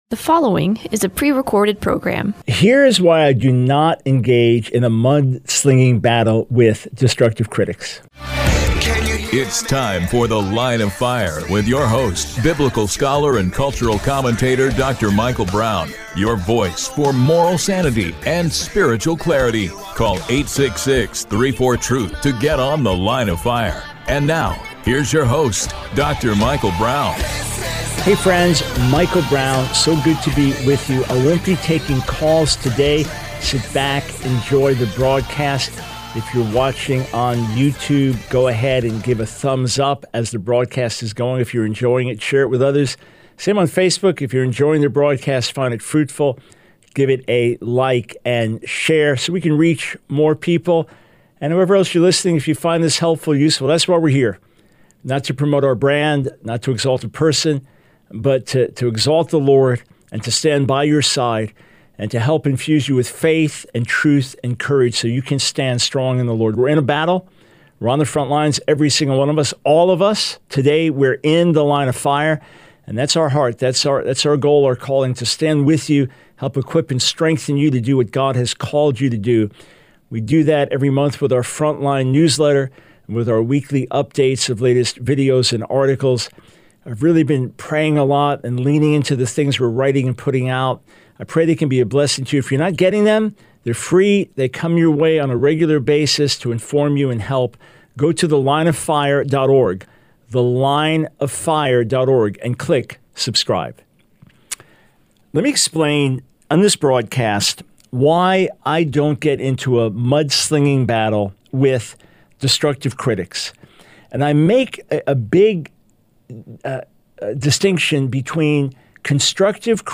The Line of Fire Radio Broadcast for 07/08/24.